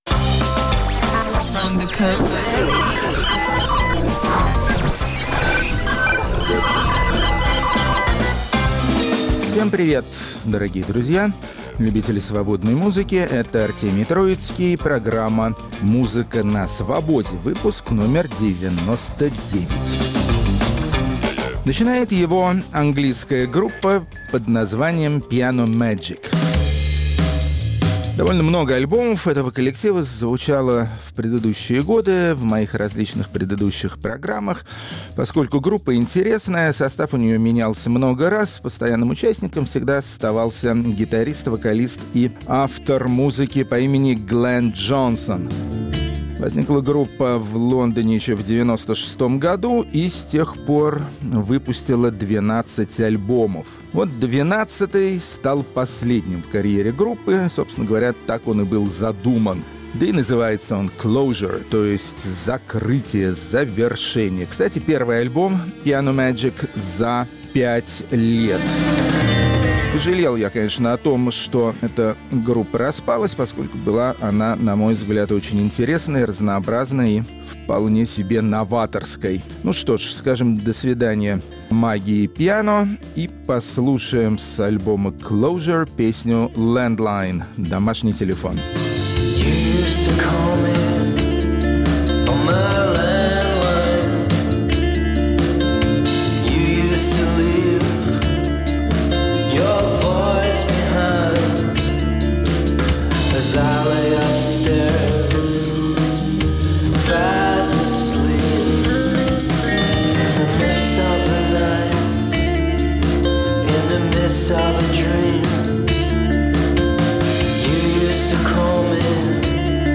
Повтор от 19 января, 2019 Хедлайнеры девяносто девятого выпуска программы "Музыка на Свободе" – российские группы альтернативного рока. Рок-критик Артемий Троицкий ищет и находит причины для новой и новой пропаганды творчества некоммерческих отечественных исполнителей, хотя его за это и критикуют.